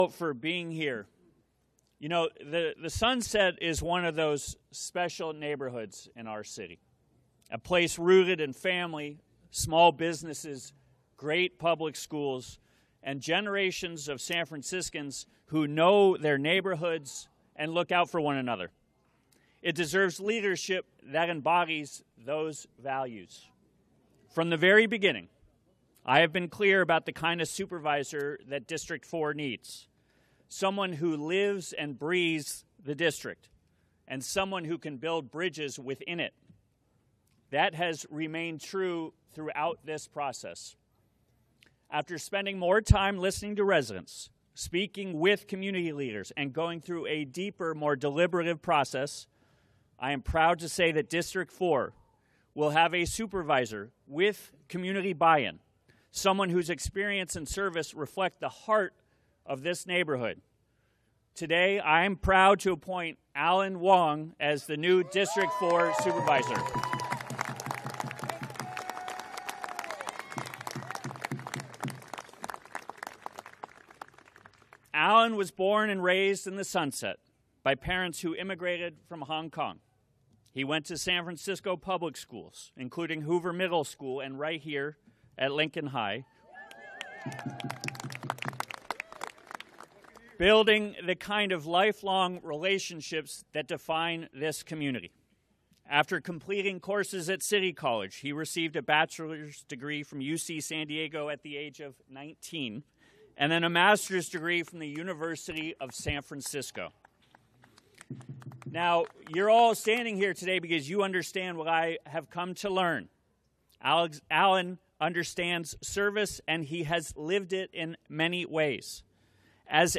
District 4 Supervisor Swearing In Ceremony - Dec 01, 2025